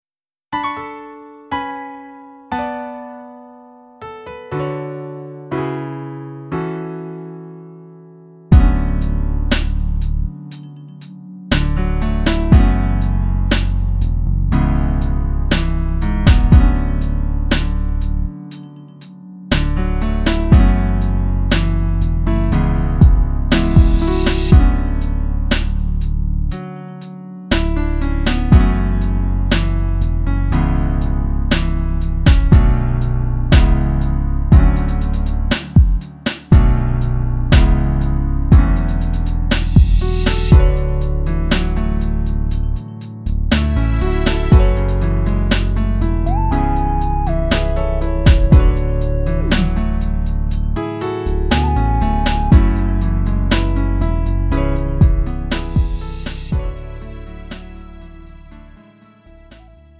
음정 -1키 3:09
장르 구분 Lite MR